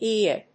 イーイーエー